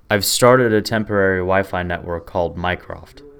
MycroftOS: Add local Mimic2 cache.